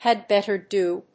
had bètter dó